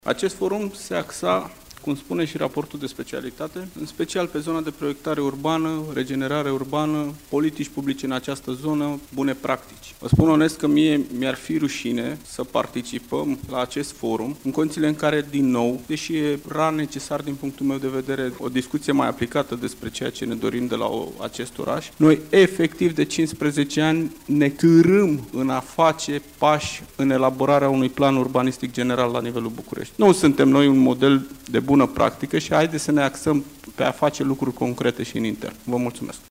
Consilierul general PSD, Adrian Vigheciu: „Noi de 15 ani ne târâm în a face pași în elaborarea unui plan urbanistic general la nivelul Bucureștiului”